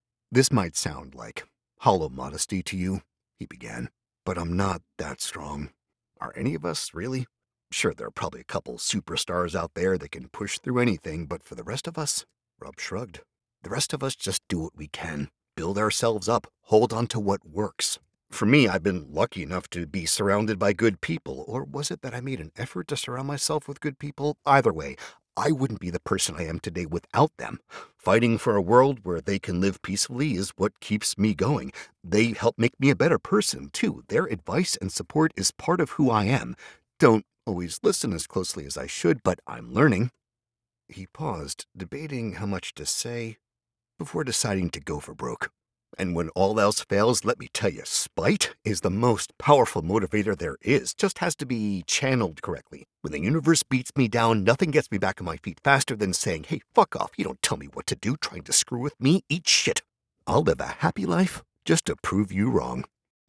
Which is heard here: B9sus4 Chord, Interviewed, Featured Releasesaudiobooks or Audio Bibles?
Featured Releasesaudiobooks